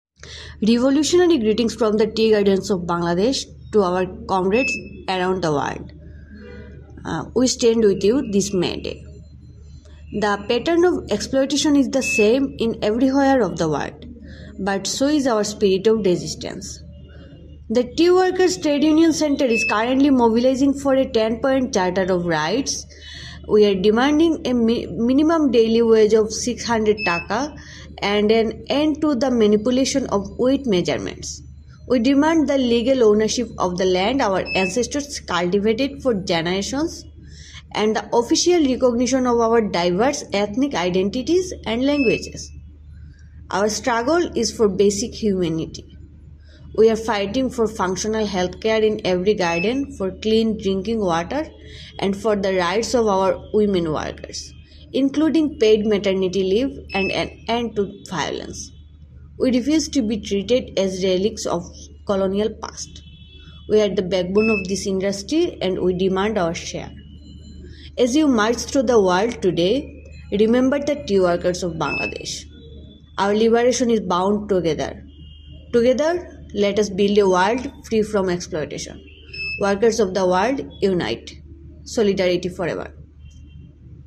Global May Day 2026: Message from Tea Workers in Bangladesh